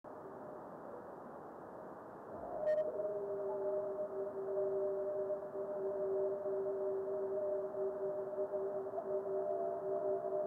video and stereo sound:
Head echo only in this movie.